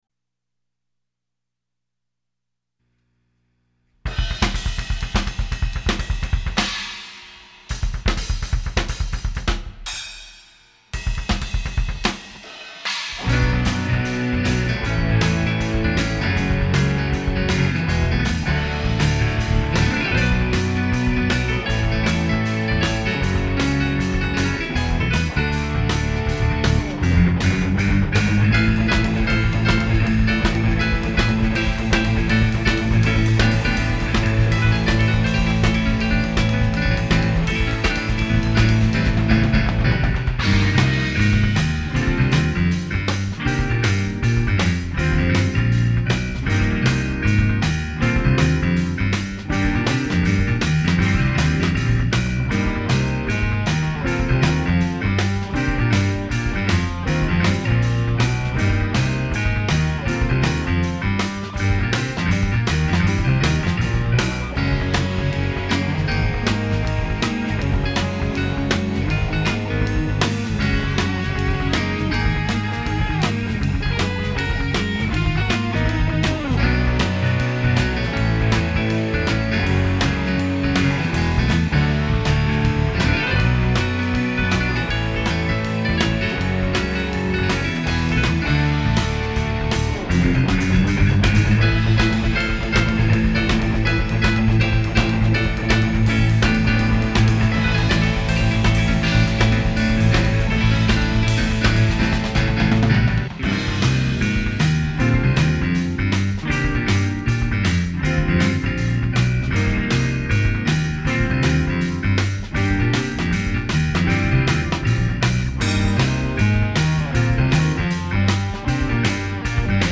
Santa Cruz home studio recordings  (1999)
3 guitars, bass and drums